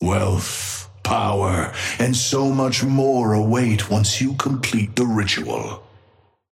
Amber Hand voice line - Wealth, power, and so much more await once you complete the ritual.
Patron_male_ally_wraith_start_05.mp3